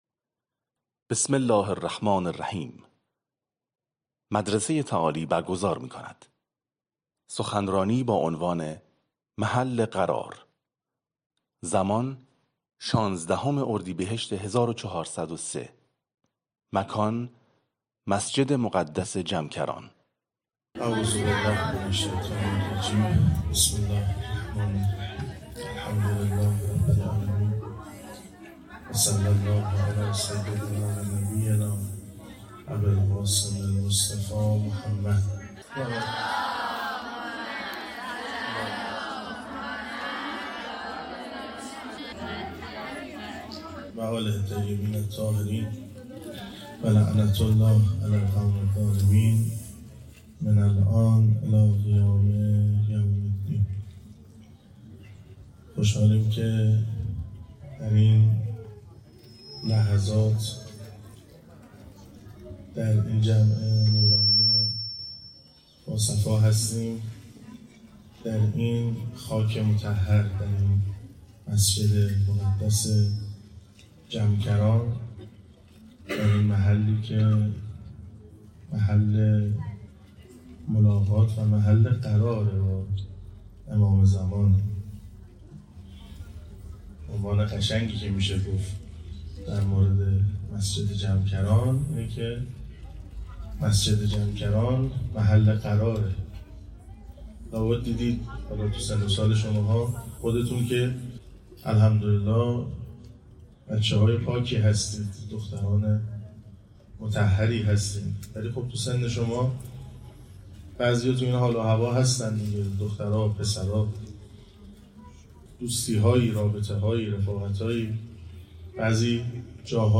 استاد خدا حفظتون کنه ممنون بابت توضیحات پیشنهاد میکنم حتما زمان بزارید این سخنرانی را گوش دهید اللهم عجل لولیک الفرج